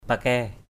/pa-kɛ:/ (d.) cắc kè, tắc kè= gecko.